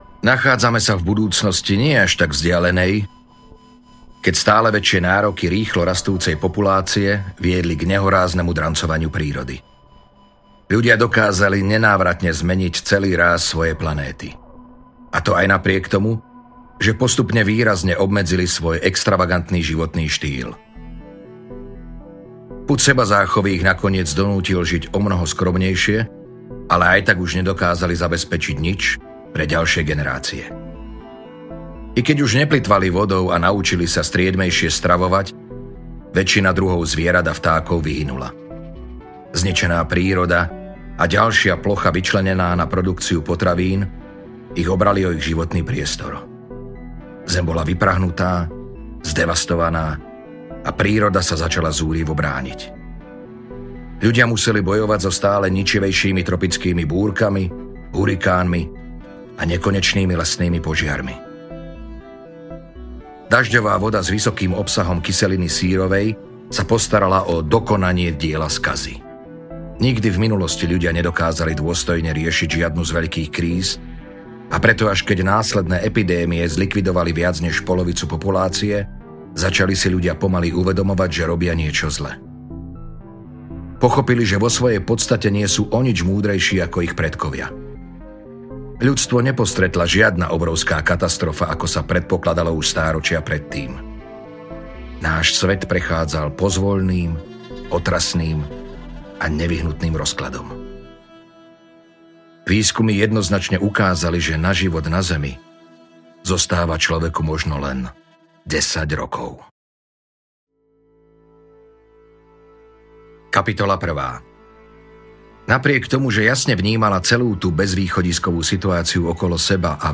Caligo audiokniha
Ukázka z knihy